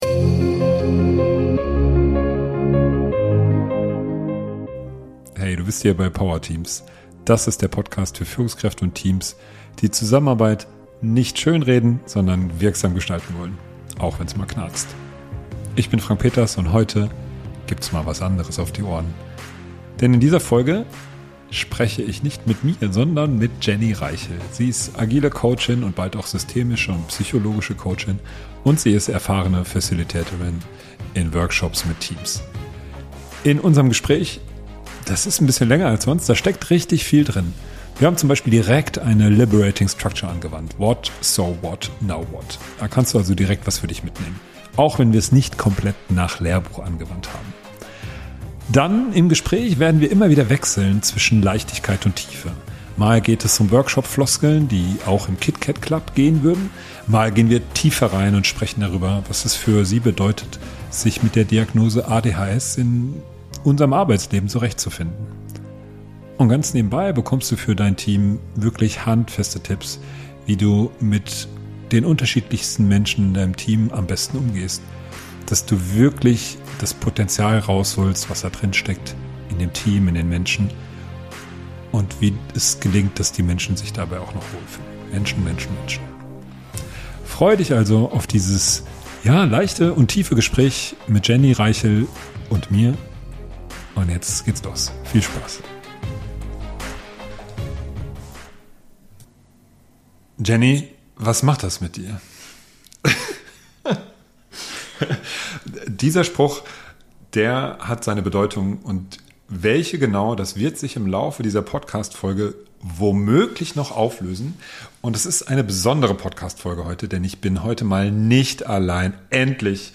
Es wird ein intensives Gespräch mit richtig viel drin: Wir wenden eine Liberating Structure direkt im Gespräch an, wir sind mal leicht, mal tief.